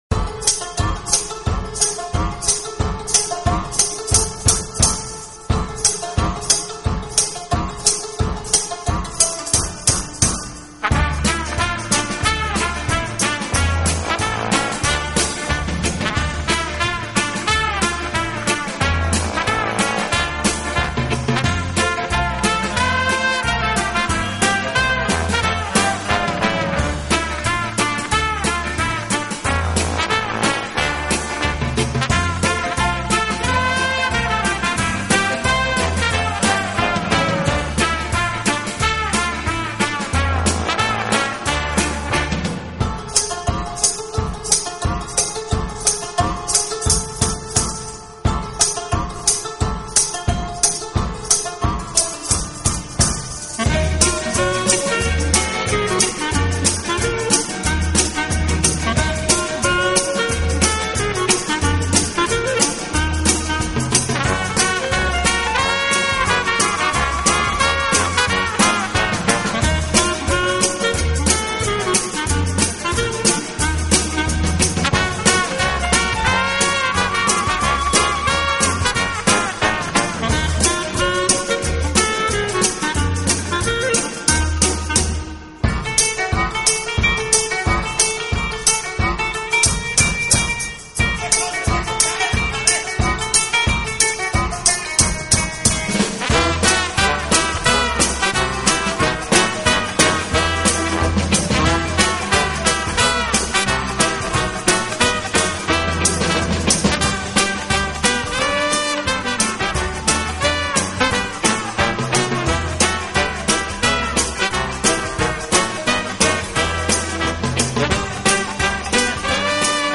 Quality: CBR 320 Kbps Mp3 / 48Khz / Joint-Stereo